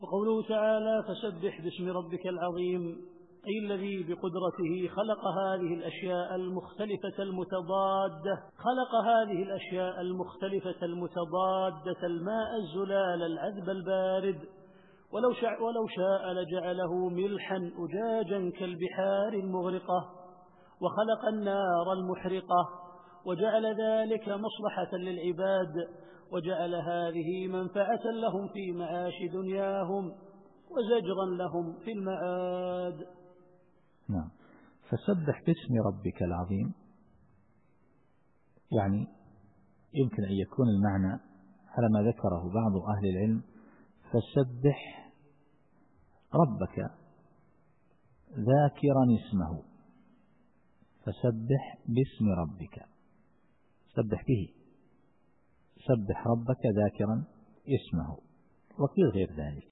التفسير الصوتي [الواقعة / 74]